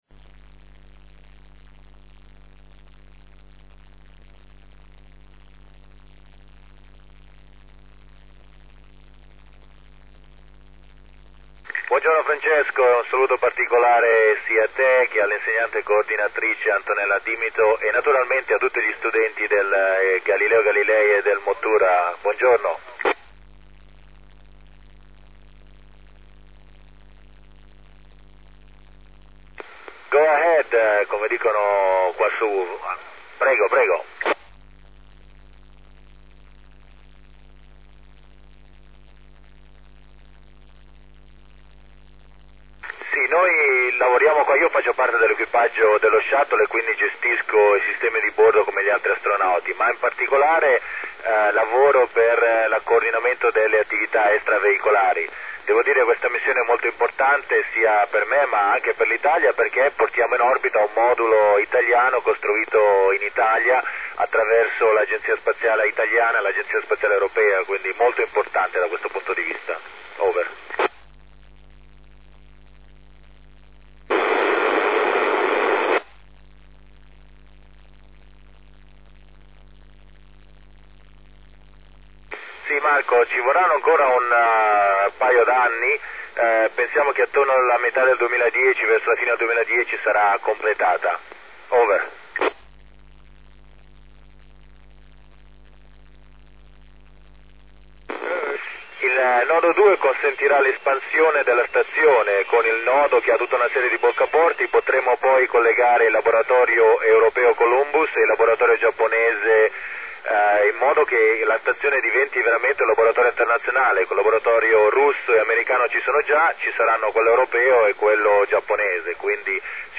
Registrazione di Nespoli quando passava sopra alla mia antenna il 31-10-07 favoloso senza nessun disturbo..................